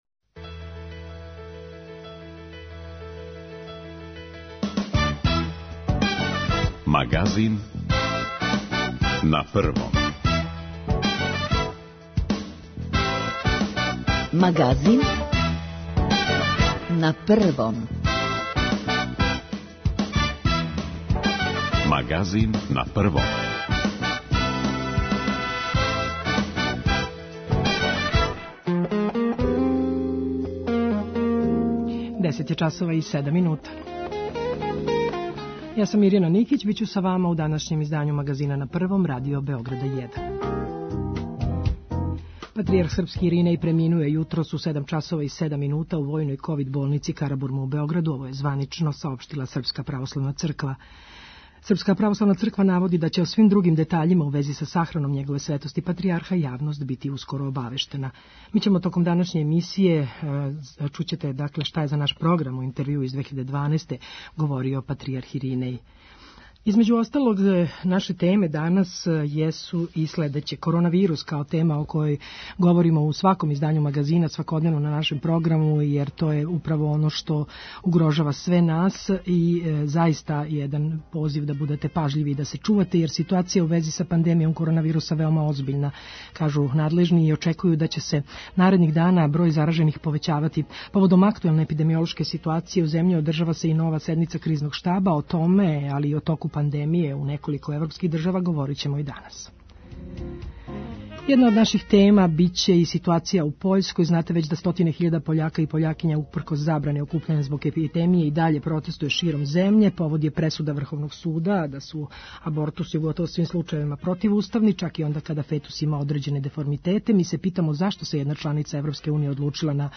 У данашњој емисији чућете шта је говорио, за шта се залагао и какве је поруке слао патријарх Иринеј. О поглавару СПЦ говоре његови најближи сарадници, свештеници, теолози, новинари, познаваоци лика и дела патријарха Иринеја.